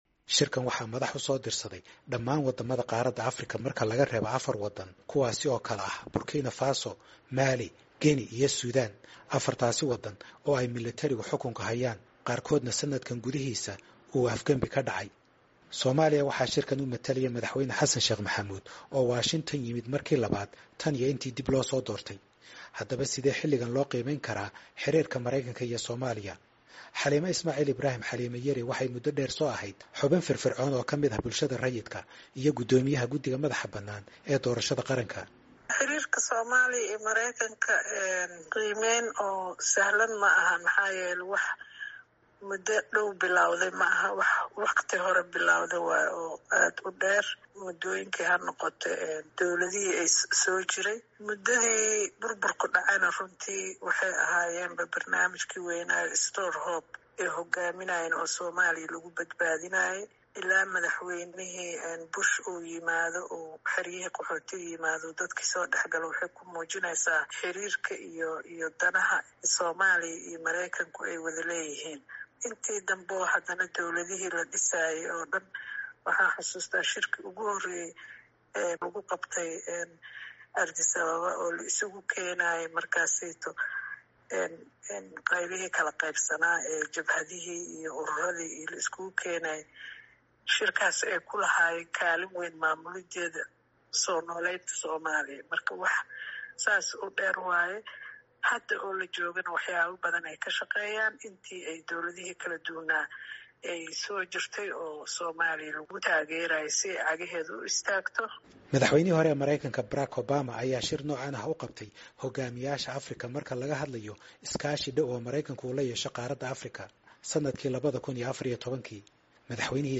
Warbixin ku saabsan iskaashiga Mareykanka iyo Soomaaliya